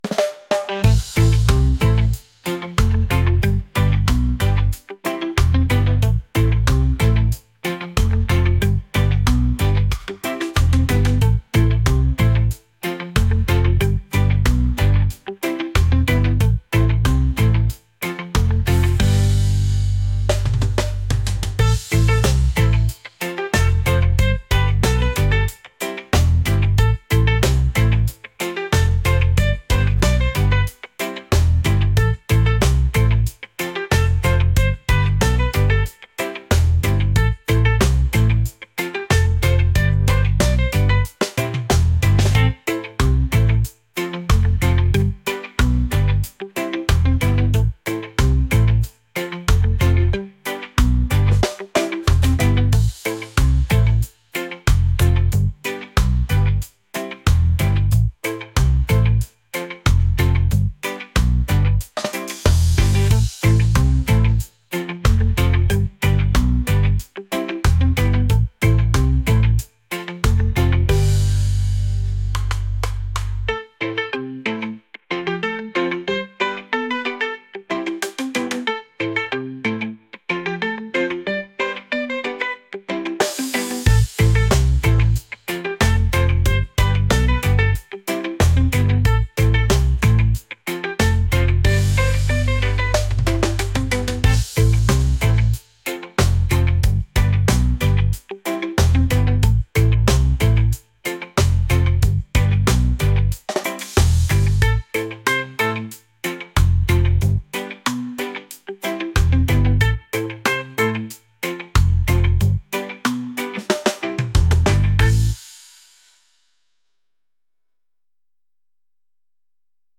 catchy | upbeat | reggae